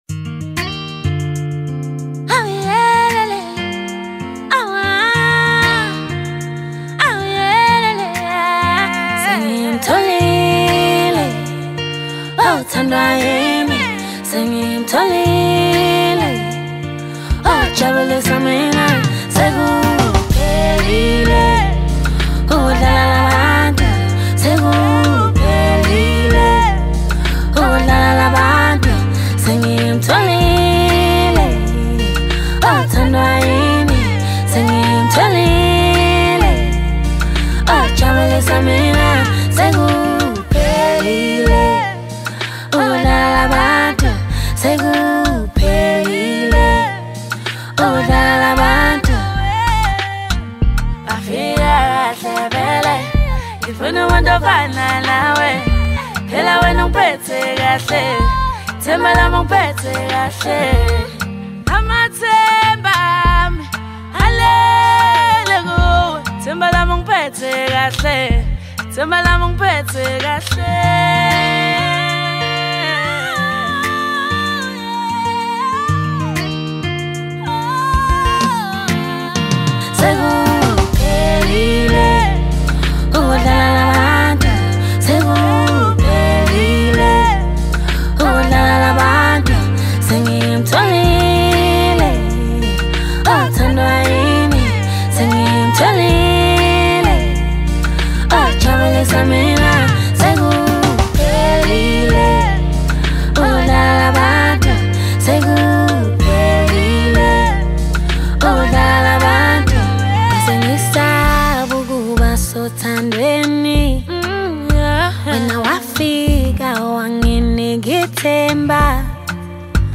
South African singer-songsmith